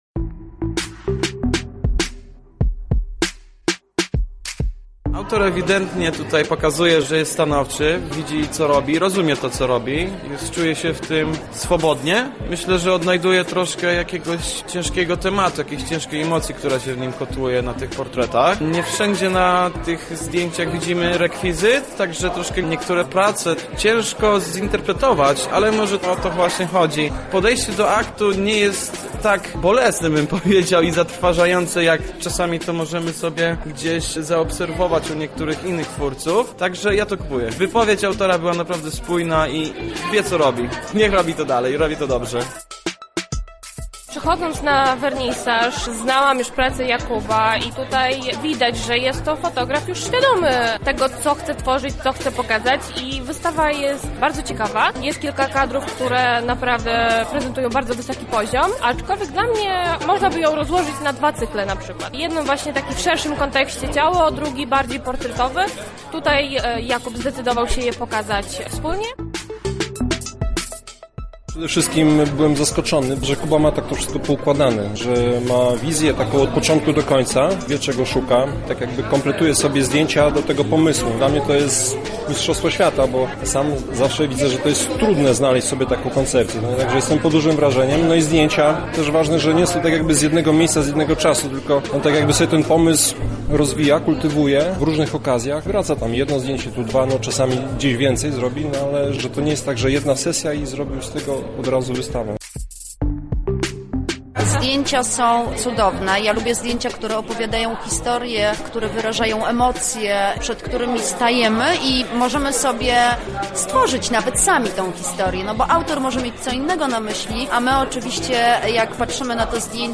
Relacja z wystawy fotograficznej